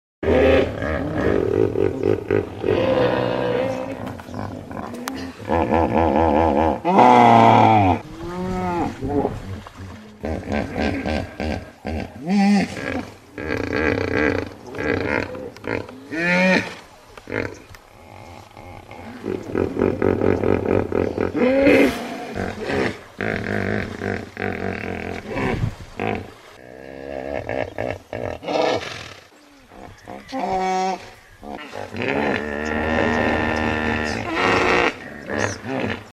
На этой странице собраны натуральные записи, которые подойдут для творческих проектов, монтажа или просто любопытства.
Глубокие рыки бегемотов в акватории зоопарка